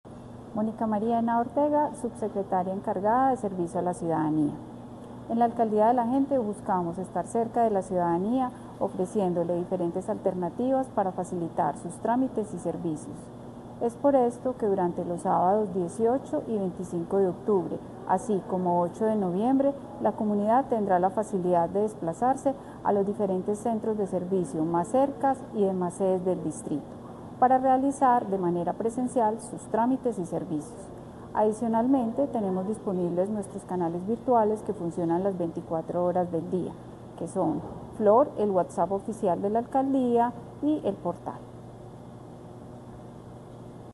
Declaraciones subsecretaria (e) de Servicio a la Ciudadanía, Mónica Henao Ortega